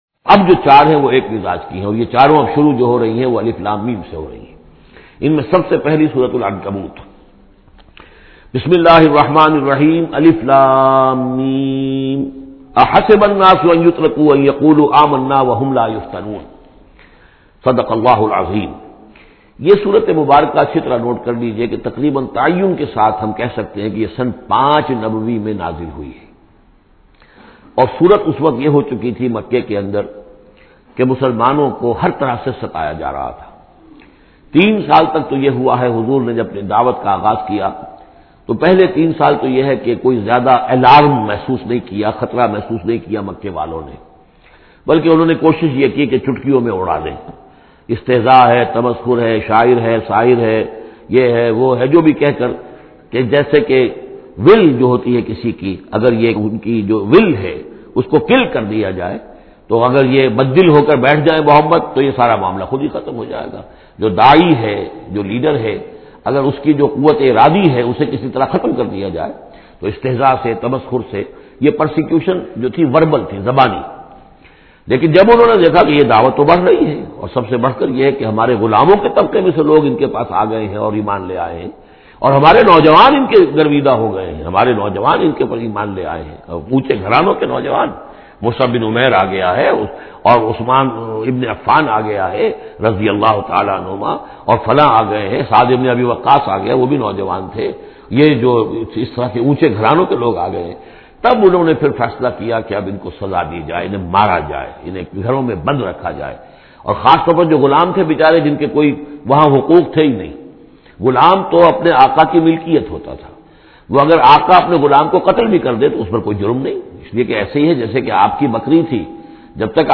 Listen urdu tafseer in the voice of Dr Israr Ahmed.